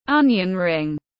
Bánh hành tiếng anh gọi là onion ring, phiên âm tiếng anh đọc là /ˈʌn.jən ˌrɪŋ/
Onion ring /ˈʌn.jən ˌrɪŋ/